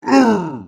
Звуки великана
Здесь собраны впечатляющие аудиоэффекты: гулкие шаги, низкие рыки, скрип древних деревьев под тяжестью гигантов.